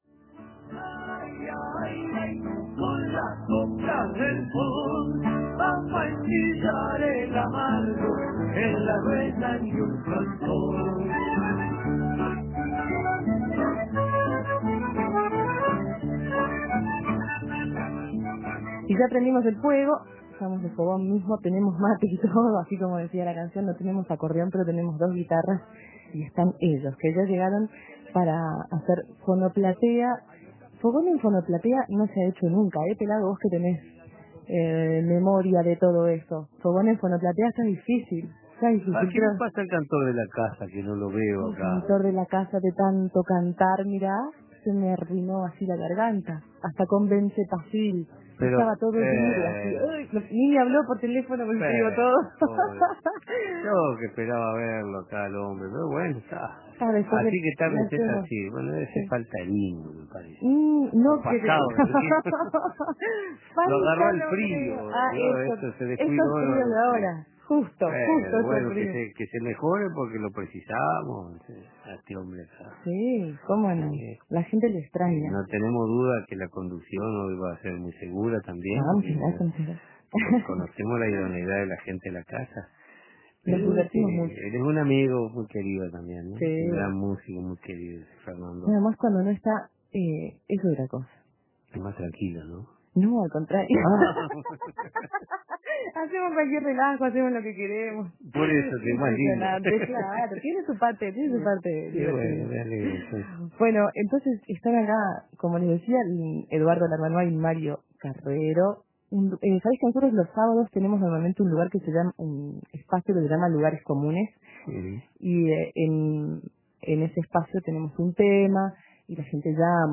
Prendimos un fueguito y nos sentamos a su alrededor mientras crepitaban las llamas y caía la noche conversamos bajito y profundo con Eduardo Larbanois y Mario Carrero, previo a la presentación de su nuevo disco Colplas del fogón, el 24, 25 y 26 de noviembre en la Sala Teatro Movie Center.